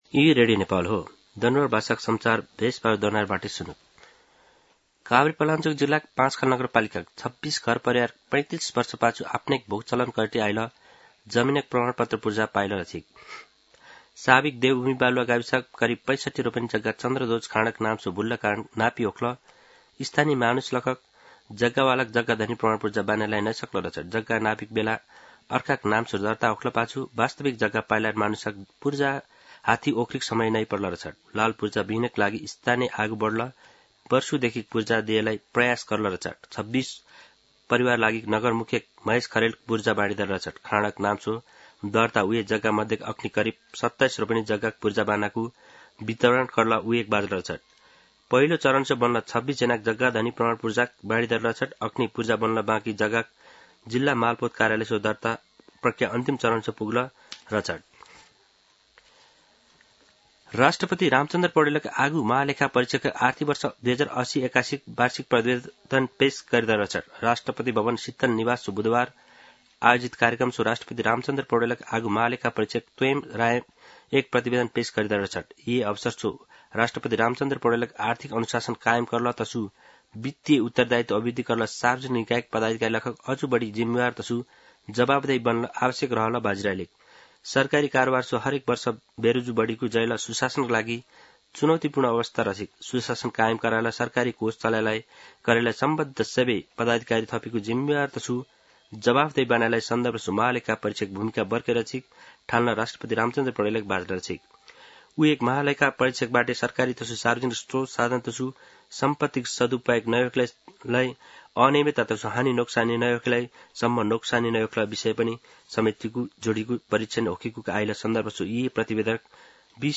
दनुवार भाषामा समाचार : ३१ वैशाख , २०८२
danuwar-news.mp3